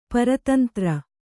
♪ para tantra